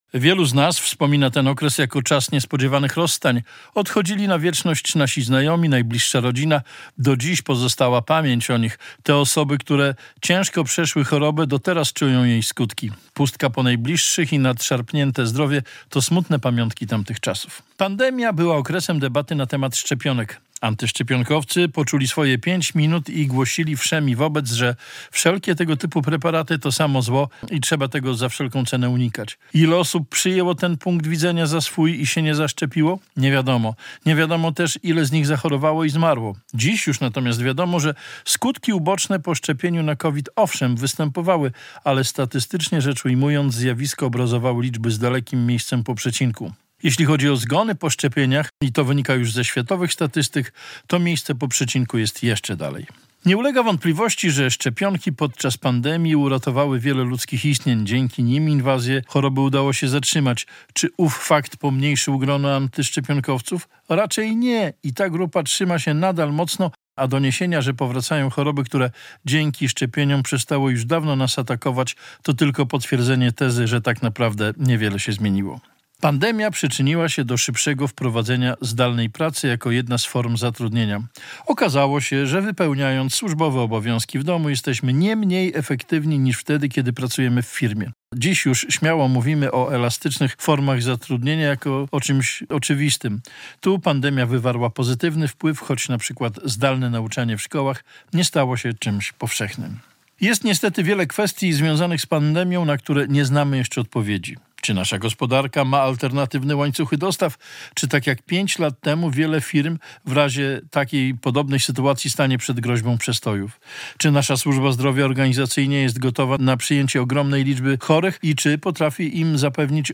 Felieton